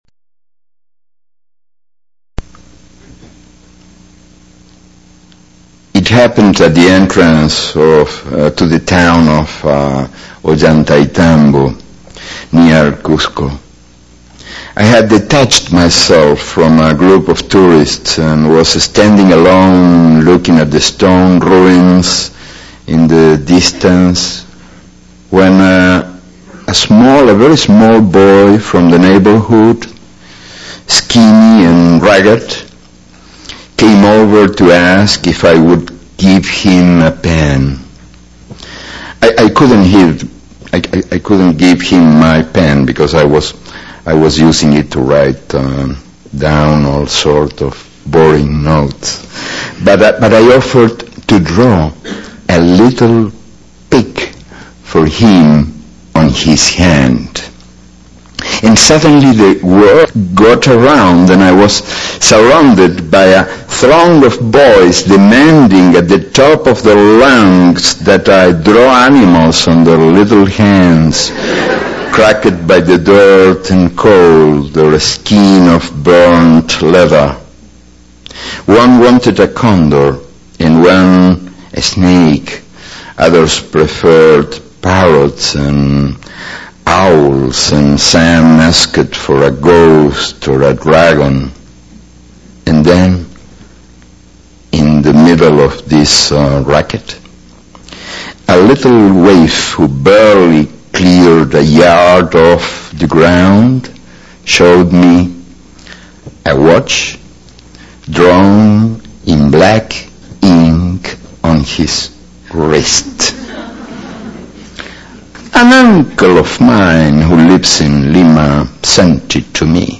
To hear Galeano read this abrazo in English,